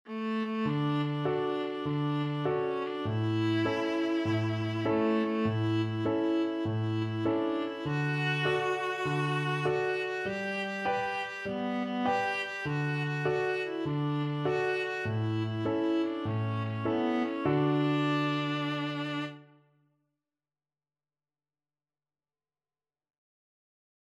Childrens Childrens Viola Sheet Music If You're Happy And You Know It
4/4 (View more 4/4 Music)
D major (Sounding Pitch) (View more D major Music for Viola )
With a swing!
Viola  (View more Beginners Viola Music)